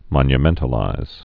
(mŏnyə-mĕntl-īz)